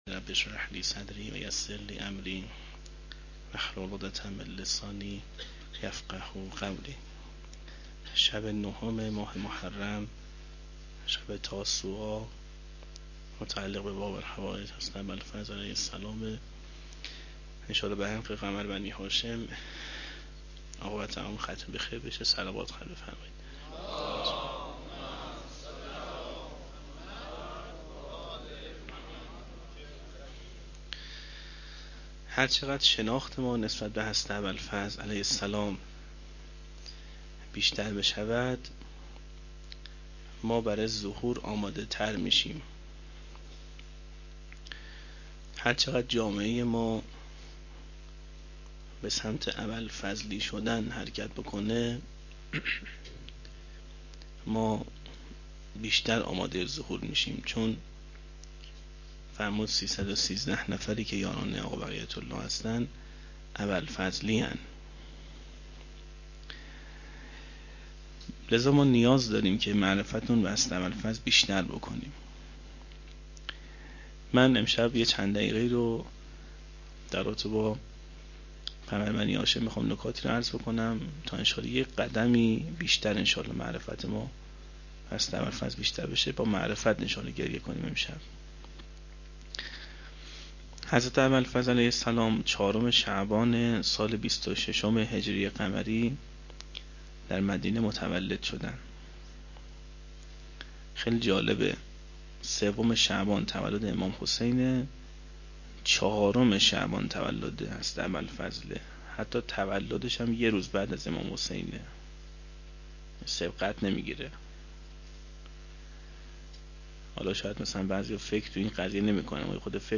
01-shab9-sokhanrani.mp3